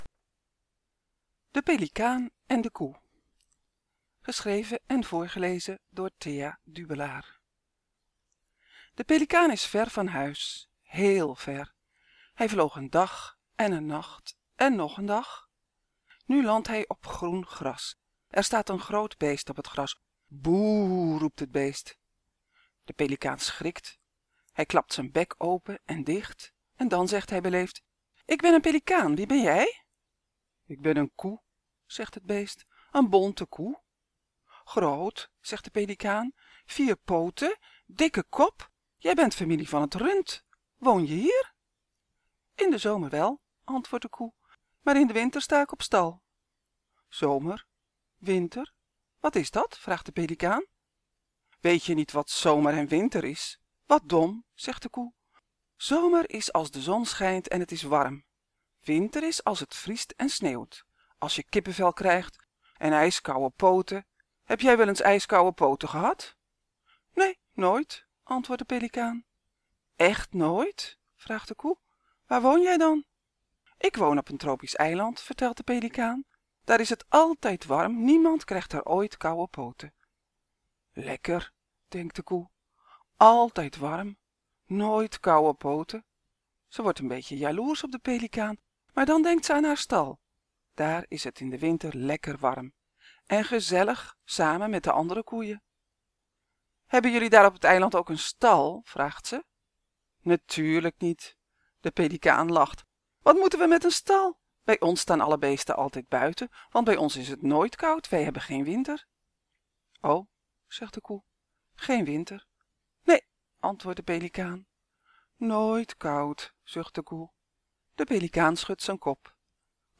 In de Kinderboekenweek lees ik elke dag een verhaal voor uit de bundel ‘Vliegeren’. Vandaag een verhaal over een pelikaan die heel ver weg vliegt en een vreemd beest ontdekt…